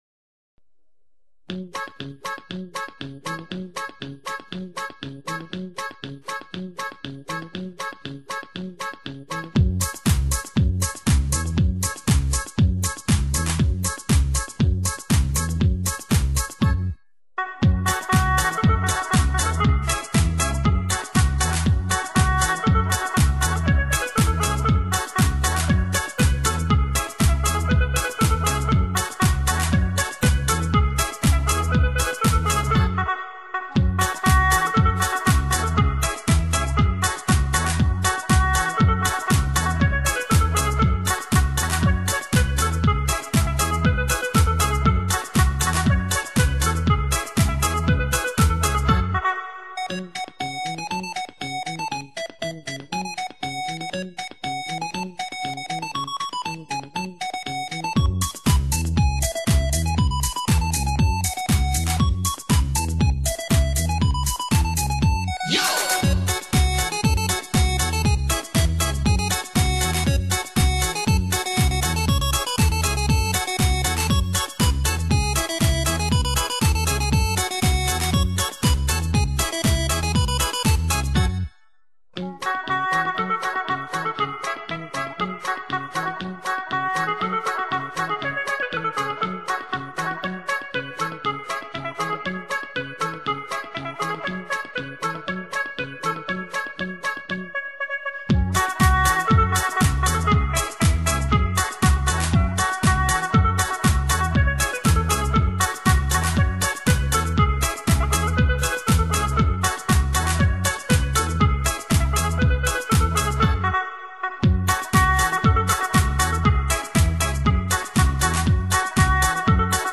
其它 此曲暂无教学 点击下载 《Ievan Polkka》（中文译名《甩葱歌》）是一首来自芬兰的波尔卡舞曲，是首都赫尔辛基的地方歌谣。